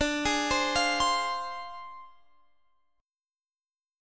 Dm7b5 Chord
Listen to Dm7b5 strummed